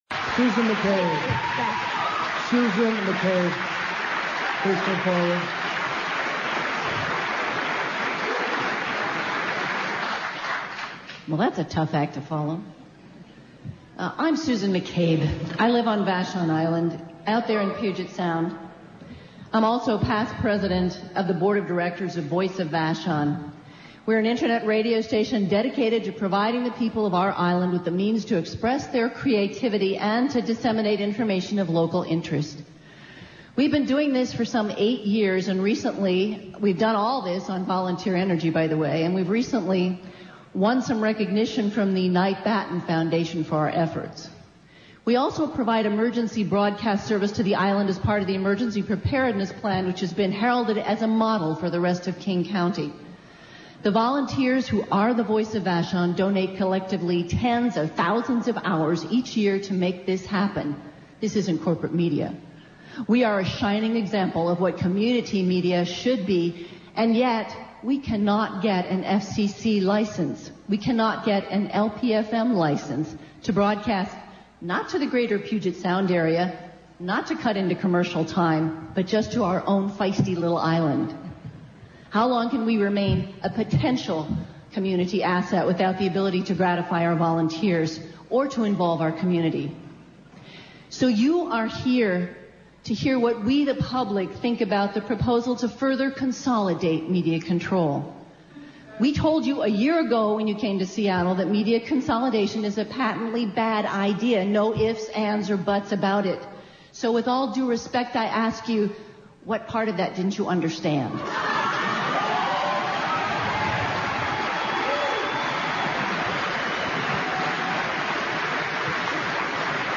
FCC holds public hearings on deregulation : Indybay